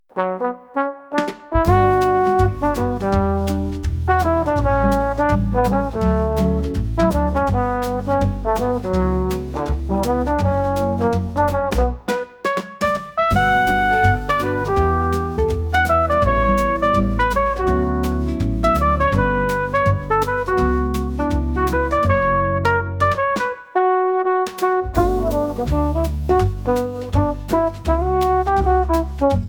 トランペットで幕間を演出するような曲です。